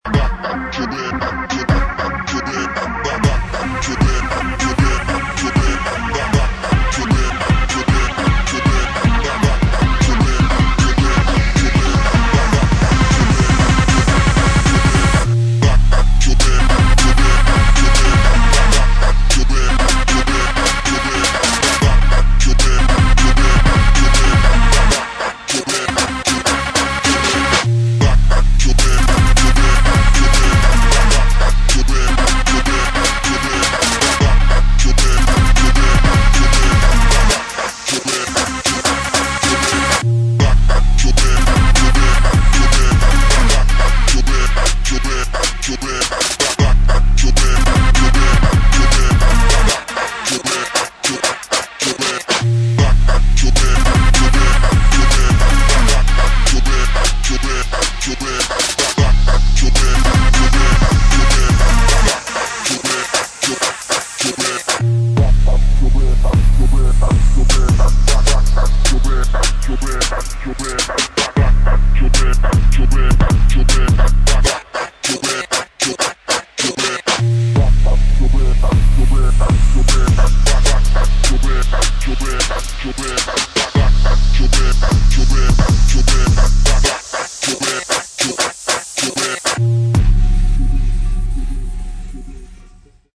[ DRUM'N'BASS / JUNGLE / BASS ]